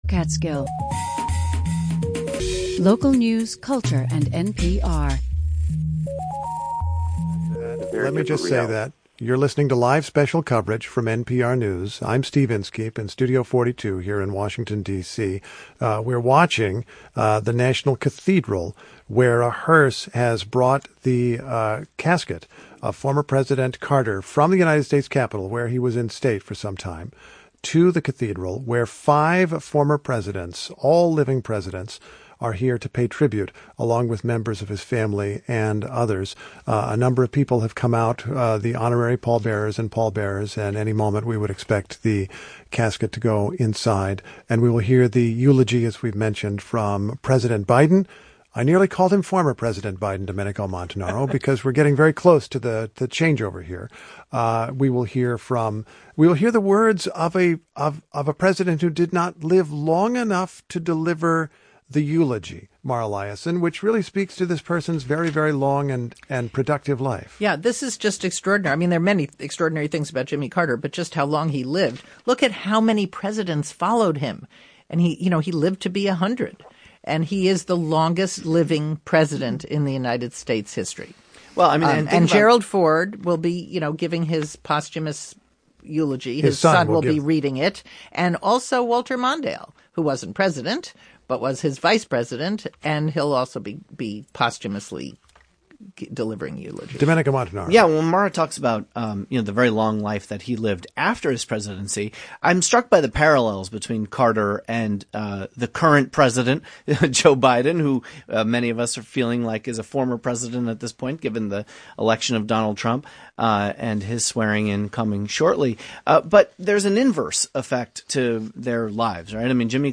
Thursday, January 9, 2025 - SPECIAL COVERAGE: President Carter Funeral
Live, local conversations focused on arts, history, and current news.